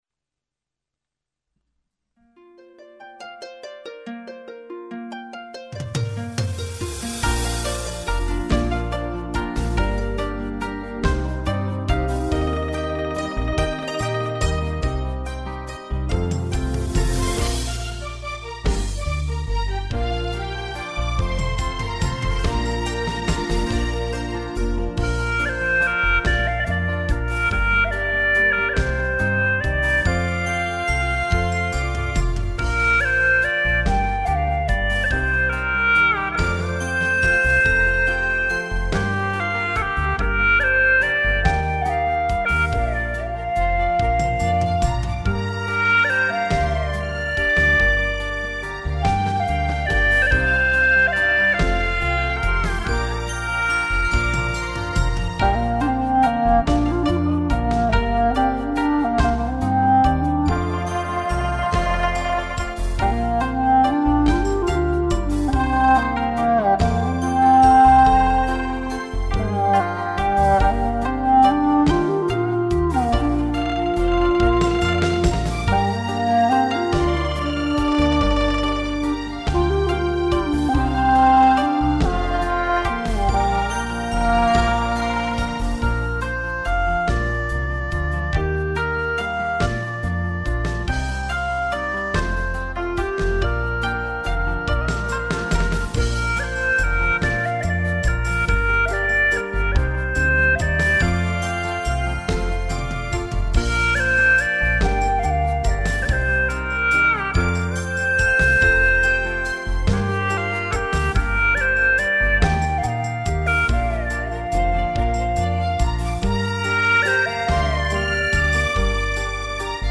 调式 : 降B
旋律婉转抒情，意味深浓